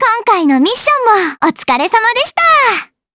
Estelle-Vox_Victory_jp.wav